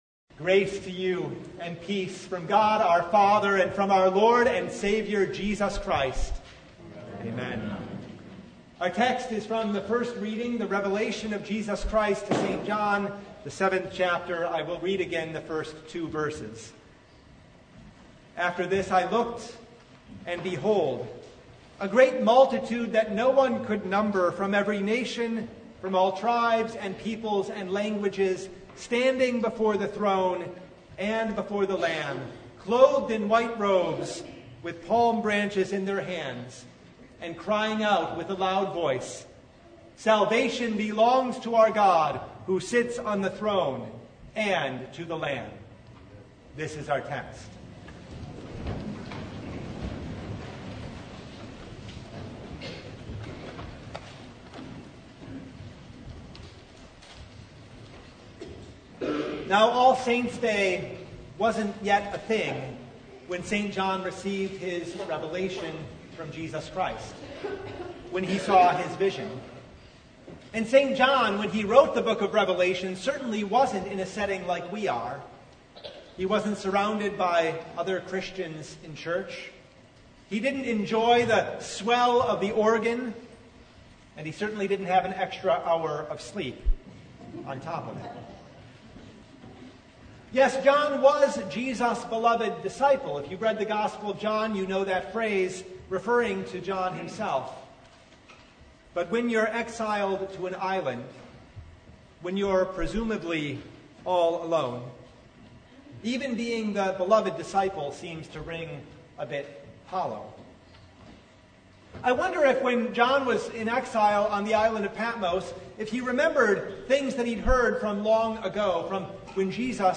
Service Type: The Feast of All Saints' Day